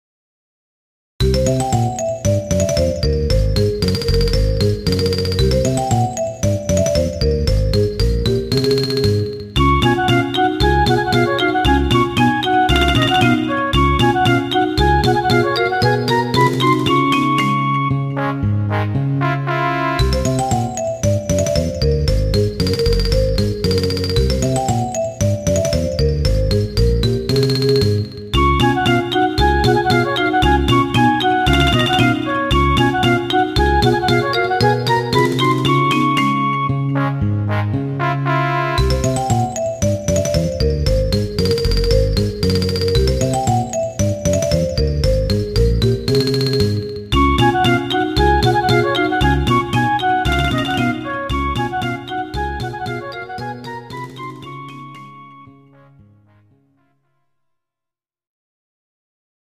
おちゃらけた感じの曲。